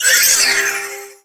Cri de Cosmog dans Pokémon Soleil et Lune.